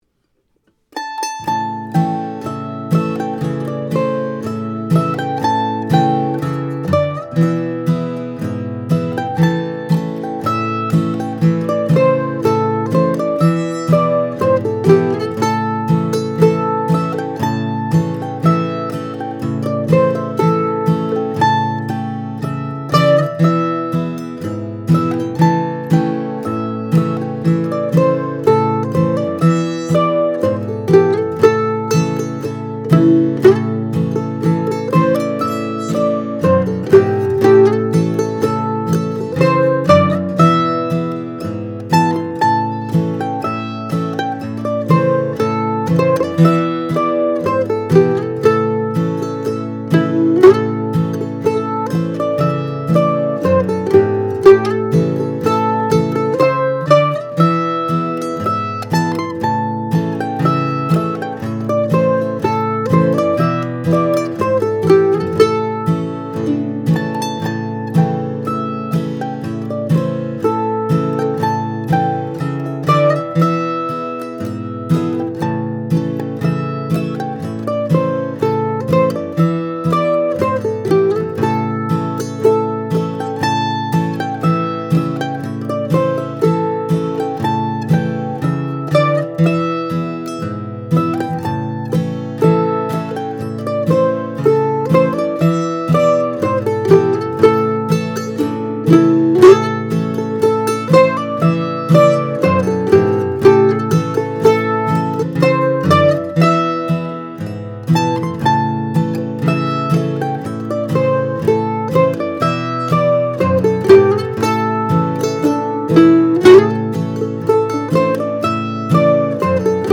Clinch Mountain Backstep | MANDOLIN
Clinch Mountain Backstep (60bpm) (.mp3 file)